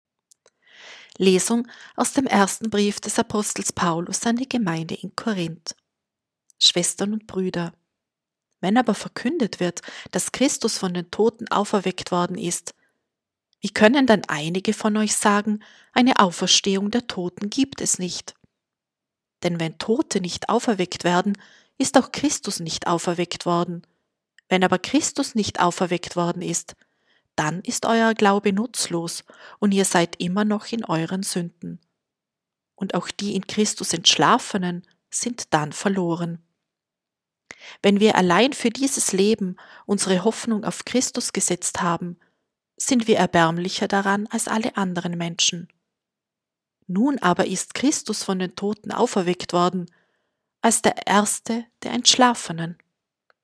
Wenn Sie den Text der 2. Lesung aus dem ersten Brief des Apostel Paulus an die Gemeinde in Korínth anhören möchten: